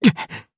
Siegfried-Vox_Damage1_jp.wav